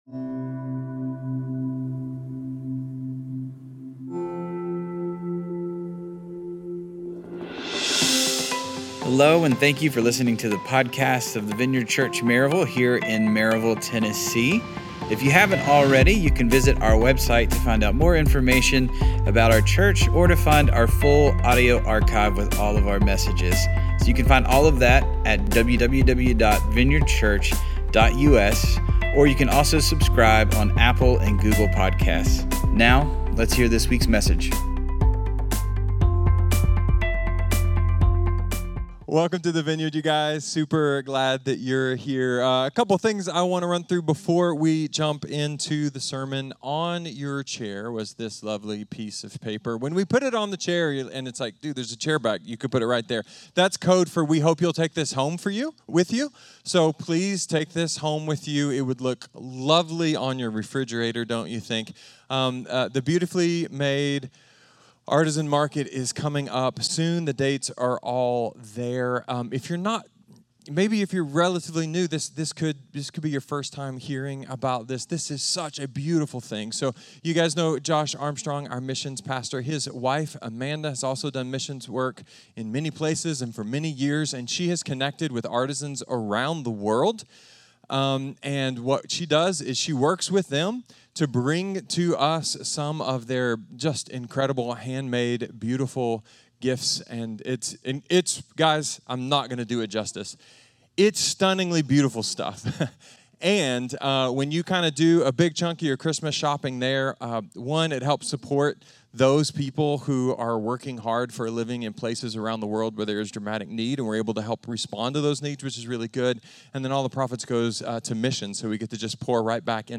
A sermon about our new vision, the stories that led up to it, and dream for what could be next.